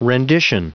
Prononciation du mot rendition en anglais (fichier audio)
Prononciation du mot : rendition